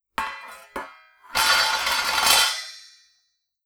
Impacts
clamour2.wav